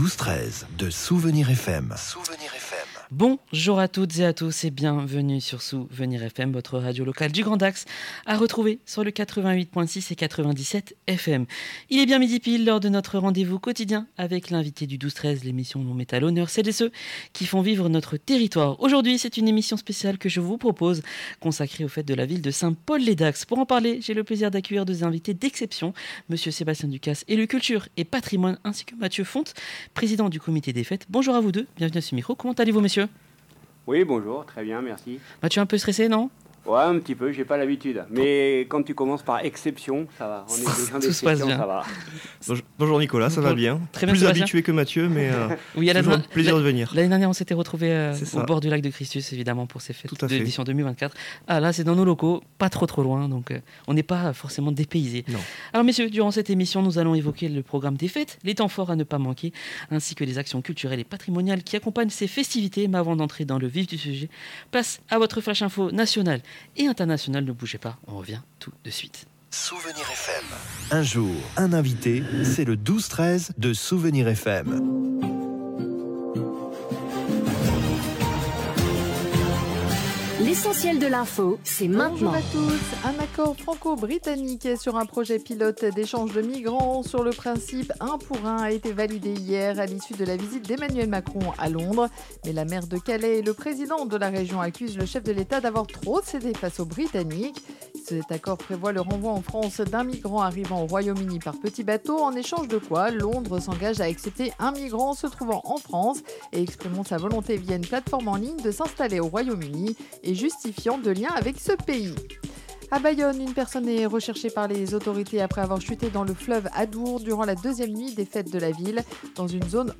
Un moment d’échange joyeux et enthousiaste, à l’image de ces fêtes populaires très attendues.